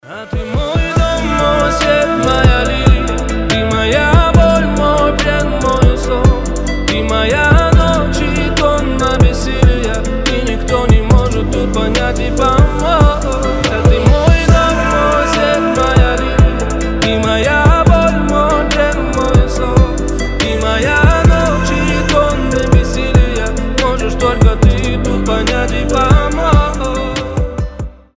• Качество: 320, Stereo
мужской голос
лирика
спокойные